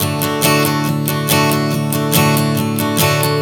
Strum 140 D 02.wav